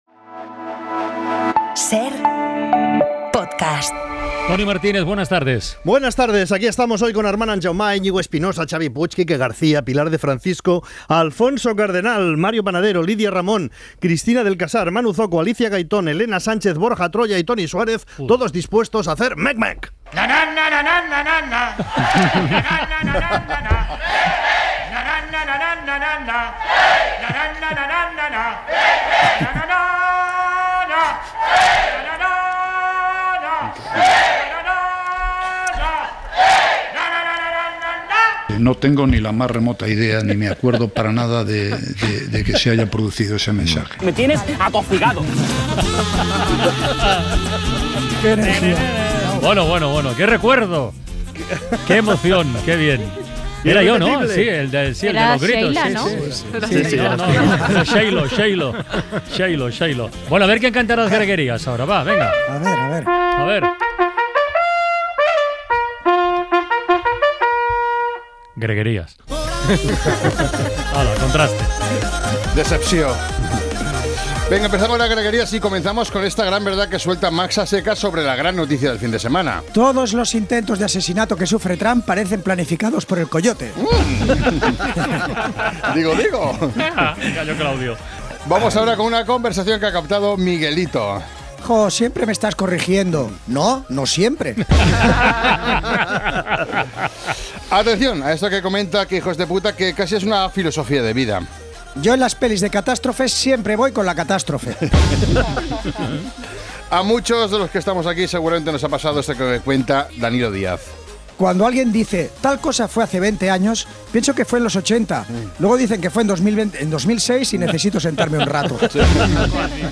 Audio de Humor en la Cadena SER en Podium Chile